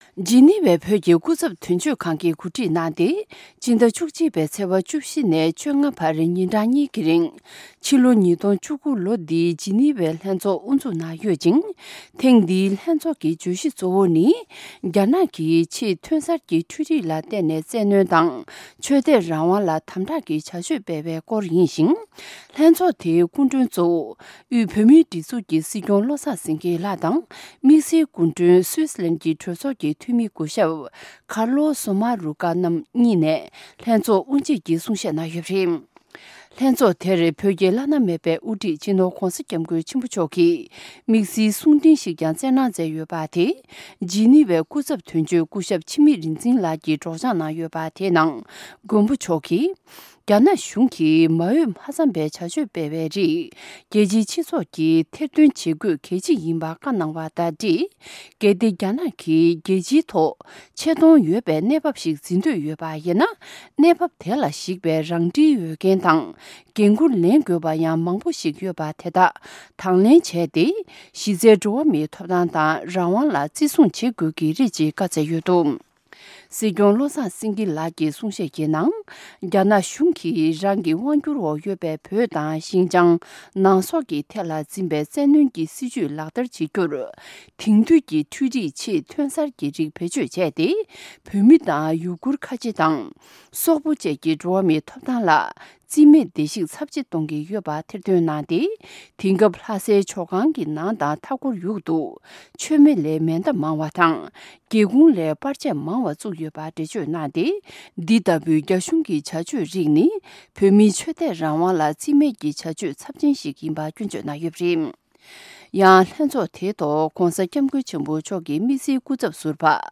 ༄༅།། དབུས་བོད་མིའི་སྒྲིག་འཛུགས་ཀྱི་སྲིད་སྐྱོང་བློ་བཟང་སེངྒེས་ལོ་འདིའི་ཇཱི་ཎི་ཝྭའི་ལྷན་ཚོགས་དབུ་འབྱེད་ཀྱི་གསུང་བཤད་གནང་ཡོད་ཅིང་།